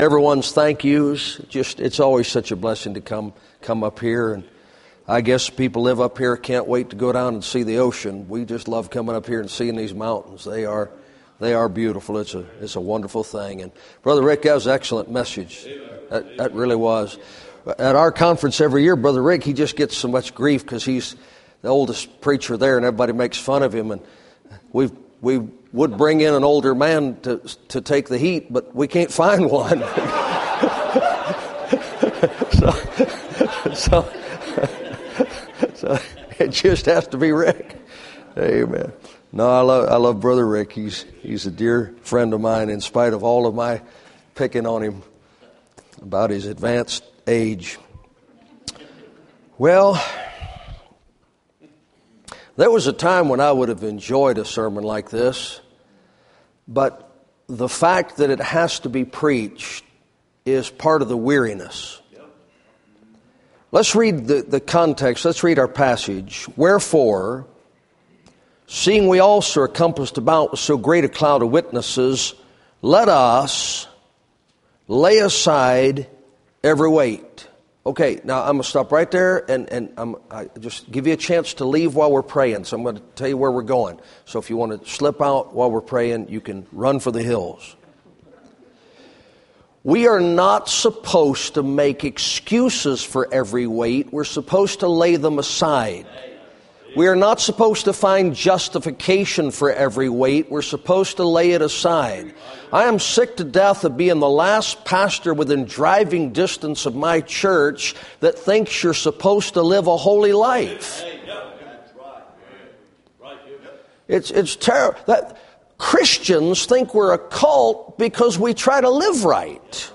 2017 Bible Conference Looking Unto Jesus